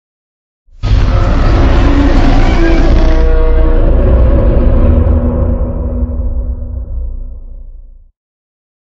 Okay so basically, I have this audio here for a monster and it blocked it.
I would love it if someone can explain why things get past but simple dragon stuff doesn’t. BTW here’s the .ogg. [Turn down your volume, its kinda loud.]
Excessively loud audios should, can, and will be blocked.